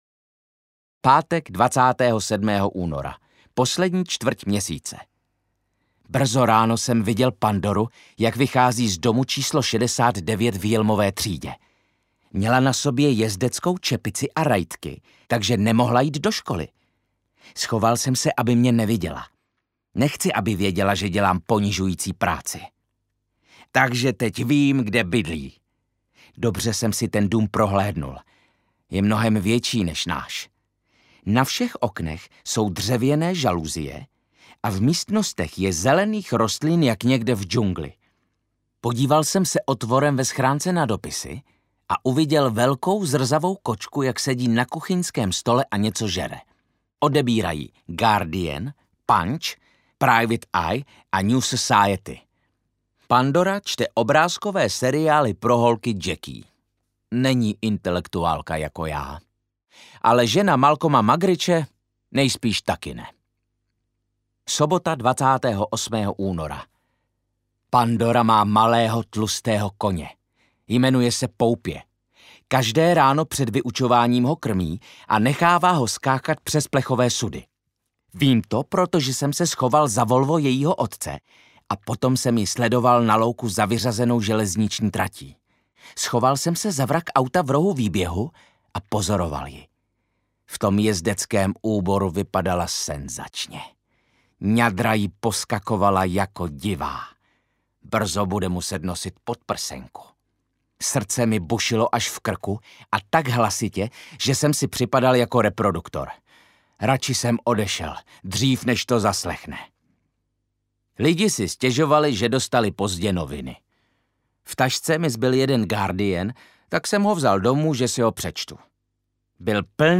Ukázka z knihy
• InterpretViktor Dvořák
tajny-denik-adriana-molea-audiokniha